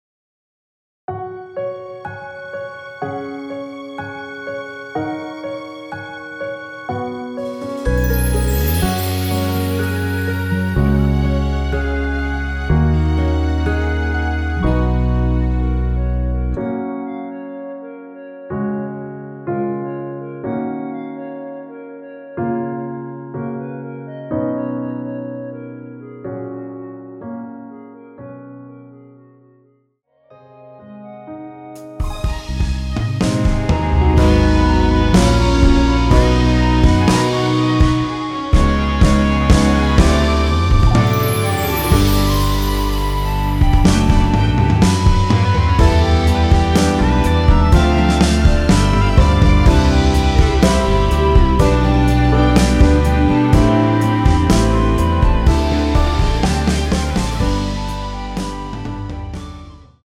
원키에서(+1)올린 멜로디 포함된 MR입니다.
F#
앞부분30초, 뒷부분30초씩 편집해서 올려 드리고 있습니다.
중간에 음이 끈어지고 다시 나오는 이유는